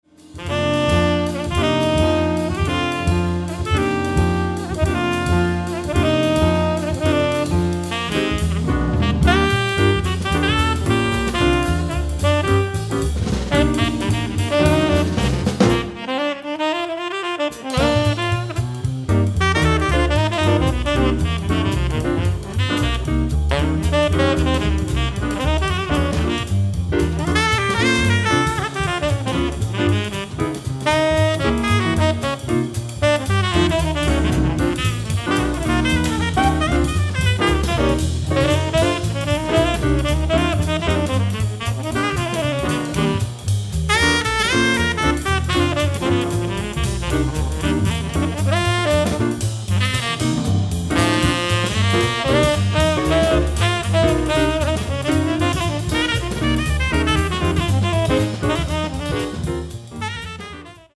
piano
tenor saxophone
acoustic bass
drums